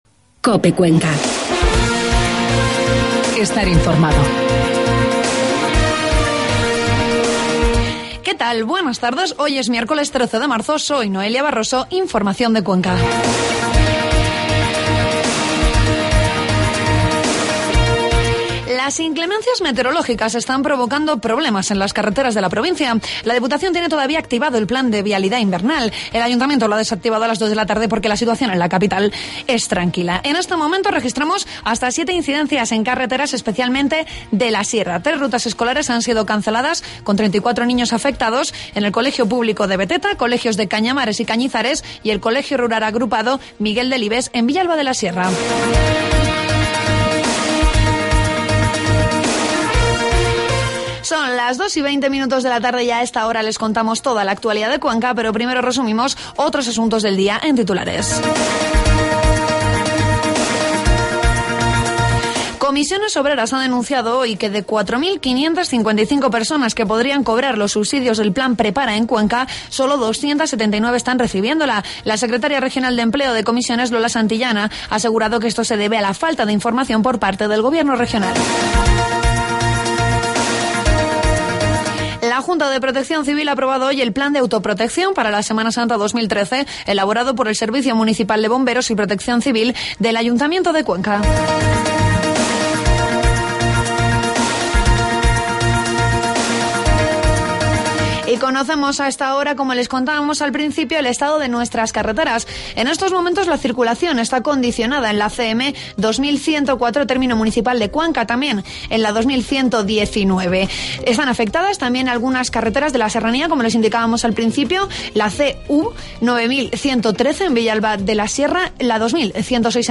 informativos de mediodía